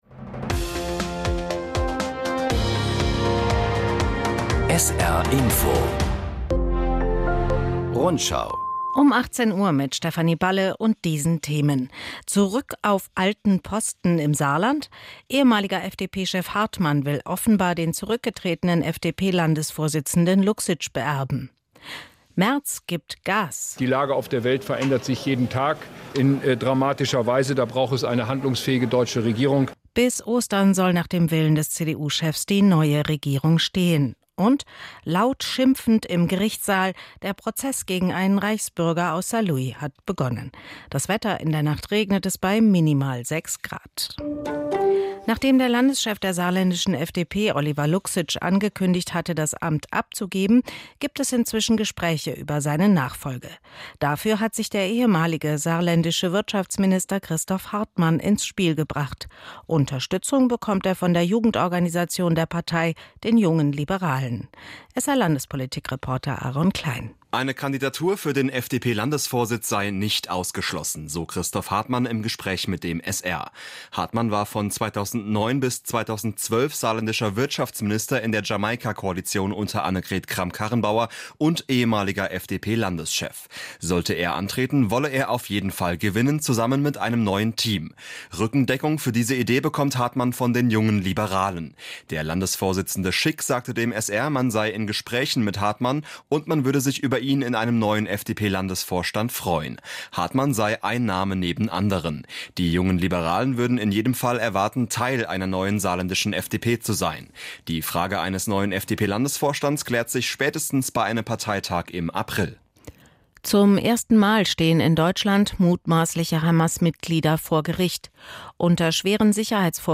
… continue reading 4 jakso # Nachrichten